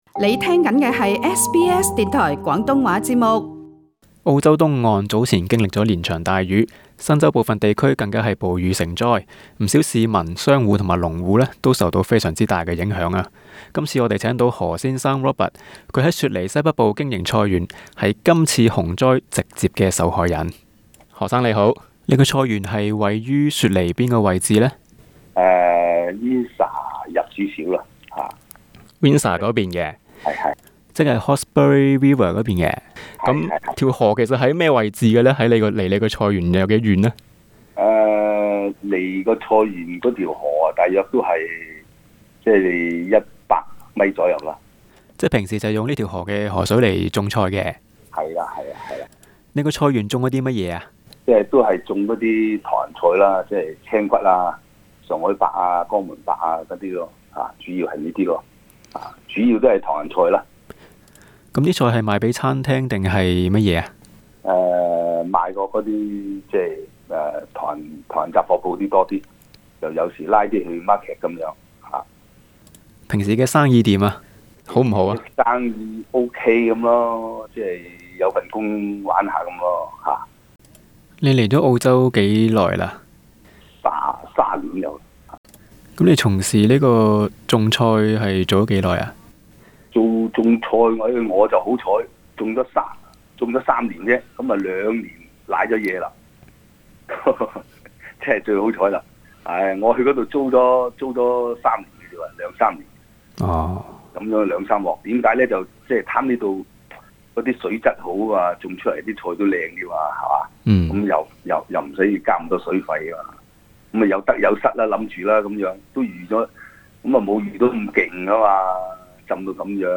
【專訪】洪水毀菜園血本無歸 華人農戶獲好地主助度難關